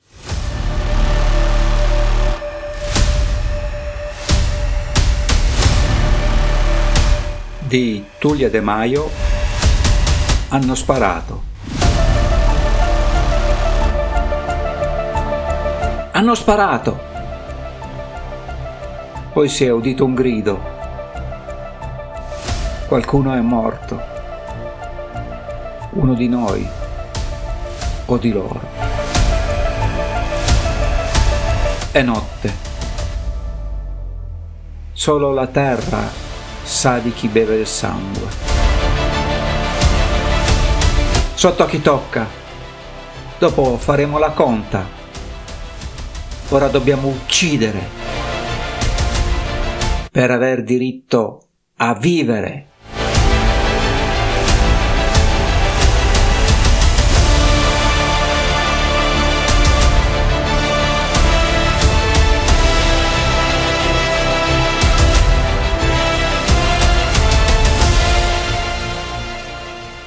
Epic war trailer music